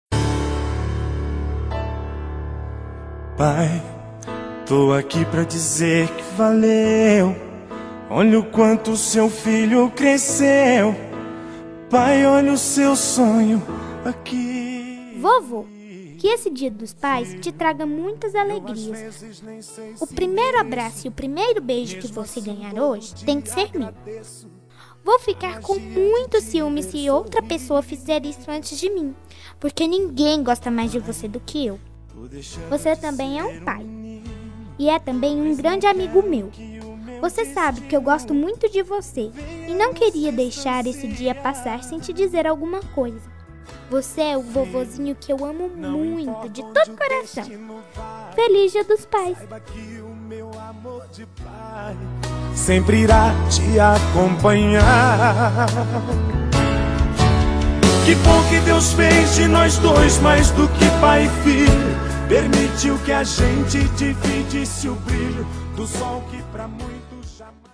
Voz de Criança